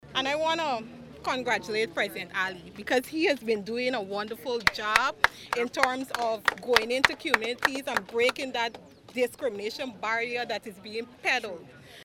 Another resident recounted how government assistance helped him during a financially challenging time.